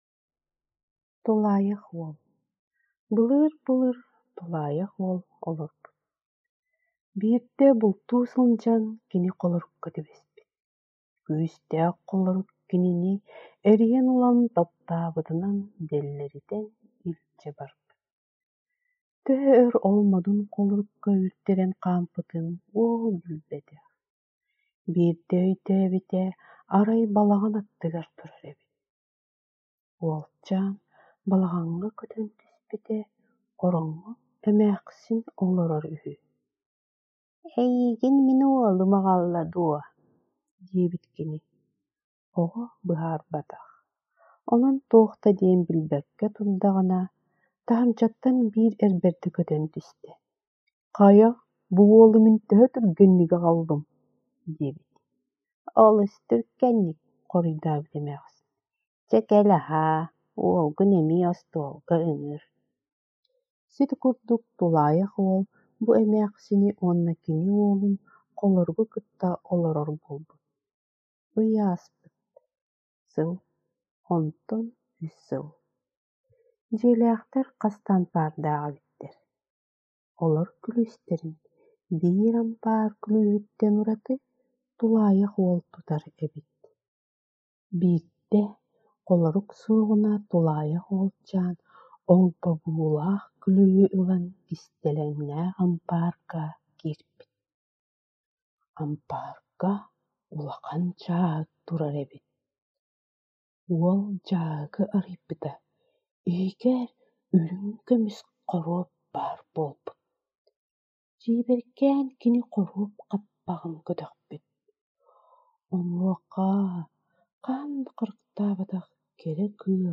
Тулаайах уол : саха остуоруйата : [аудиоостуоруйа]
Аудиокниги (Истиҥ, ааҕыы)